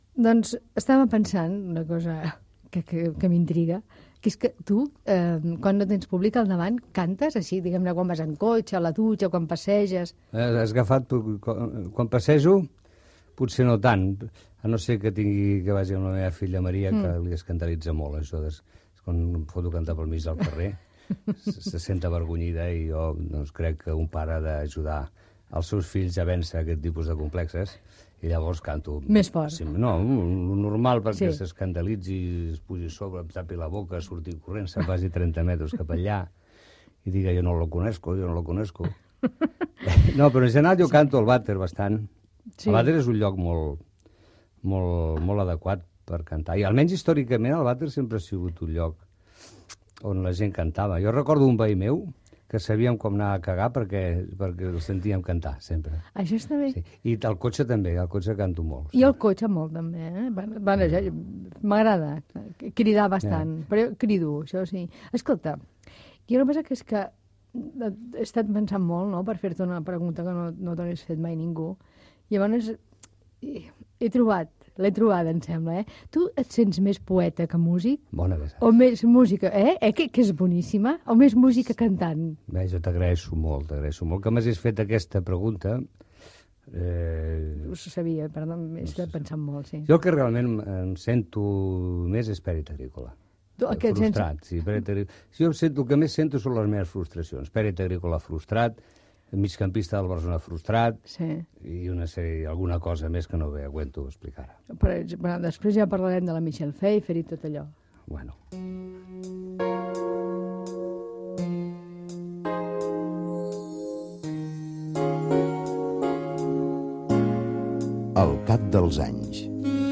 Presentació i entrevista al cantant Joan Manuel Serrat
Entreteniment